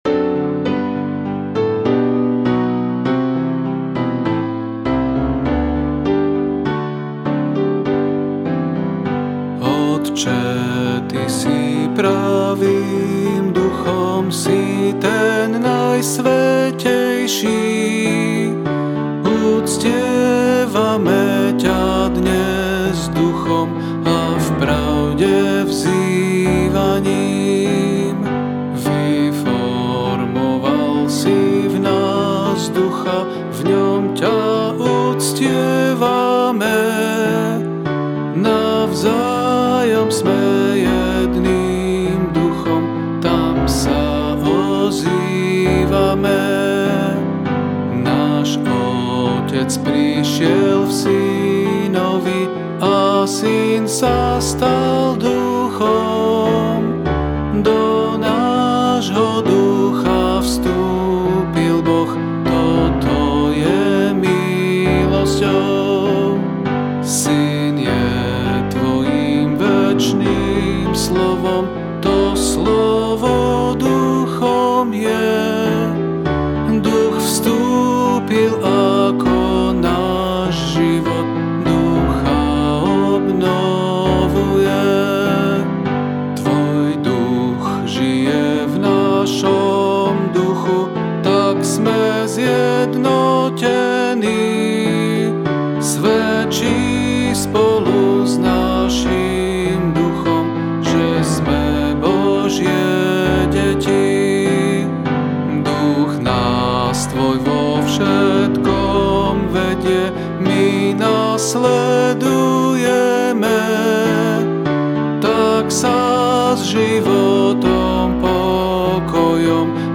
Ab Major